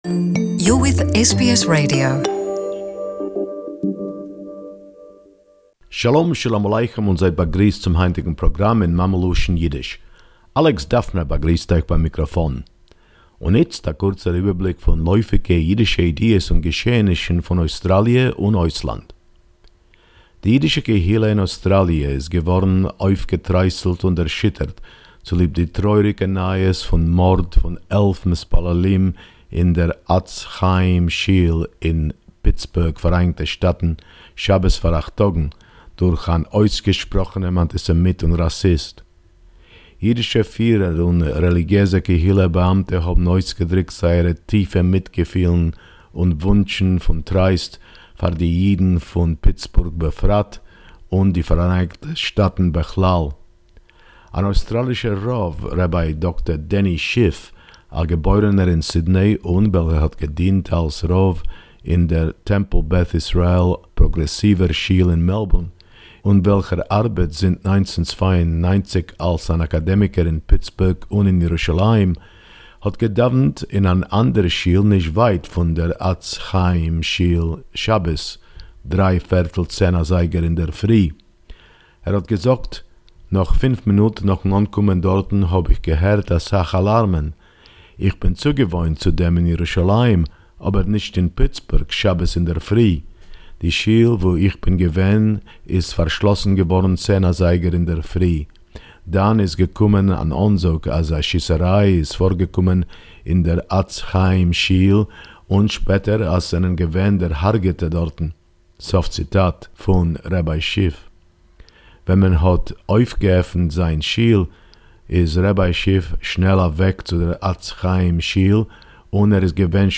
weekly report